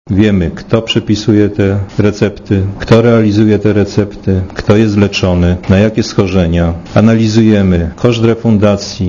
Komentarz audio Rekordzista był dentystą.